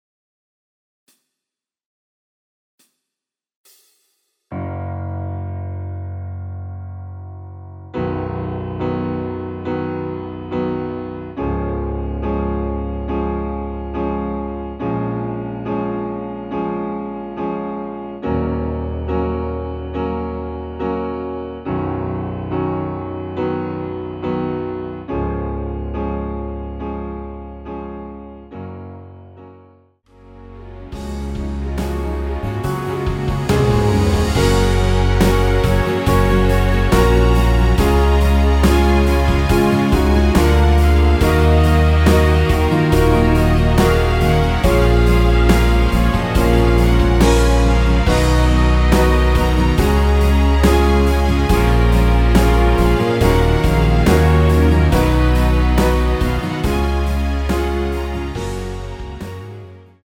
원키에서(-1)내린 MR입니다.
Gb
앞부분30초, 뒷부분30초씩 편집해서 올려 드리고 있습니다.
중간에 음이 끈어지고 다시 나오는 이유는